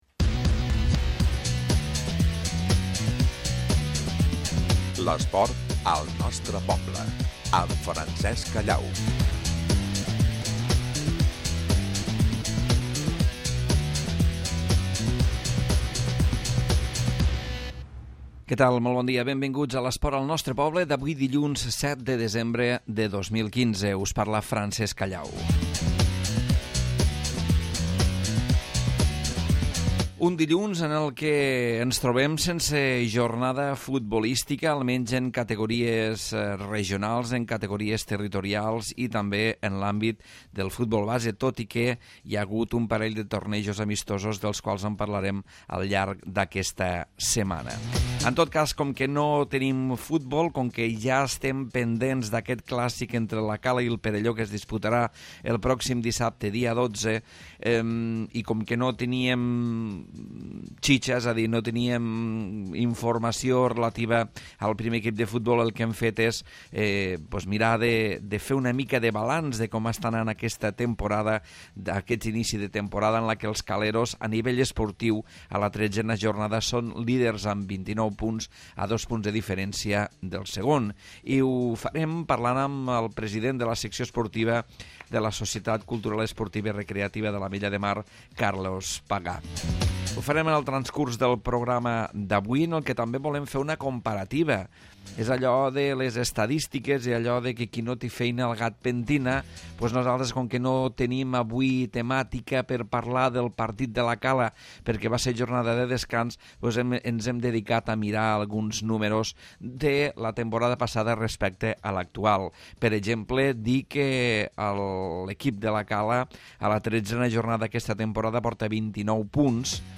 Repàs a l'actualitat esportiva de l'Ametlla de Mar. Avui entrevistem